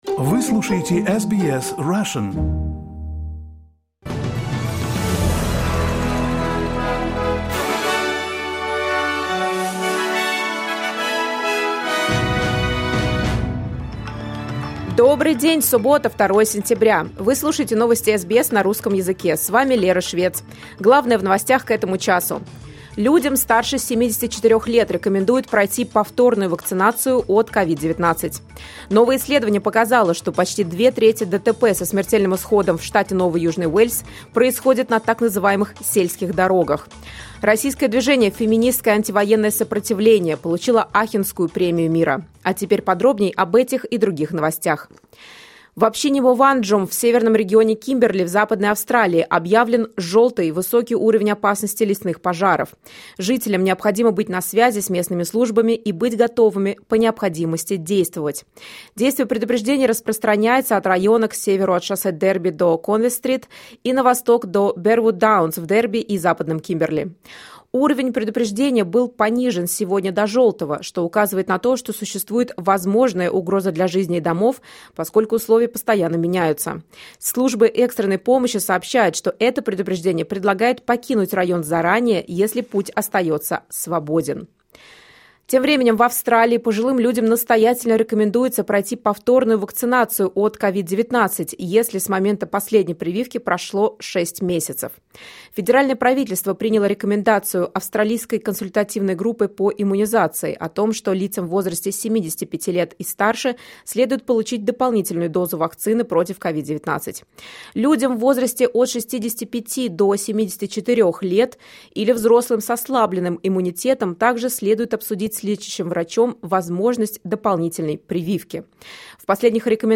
SBS news in Russian — 02.09.2023